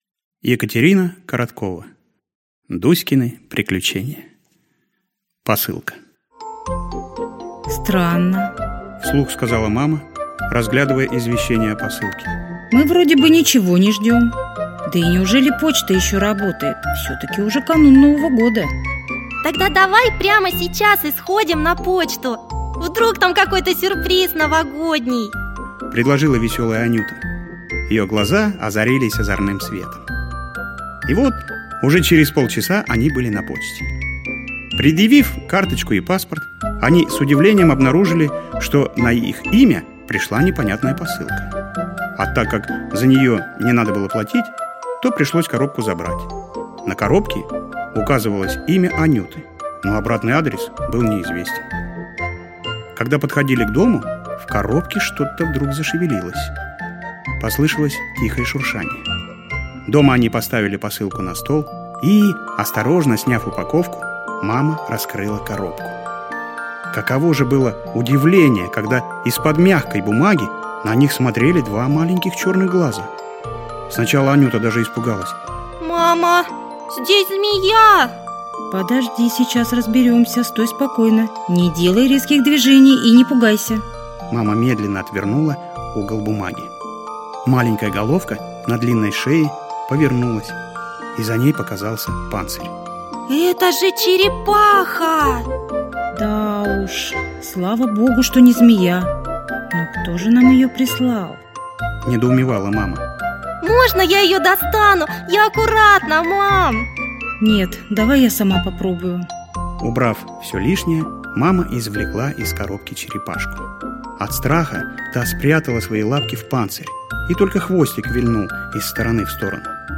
Аудиокнига Приключения черепашки Дуськи. рассказы для детей | Библиотека аудиокниг